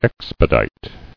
[ex·pe·dite]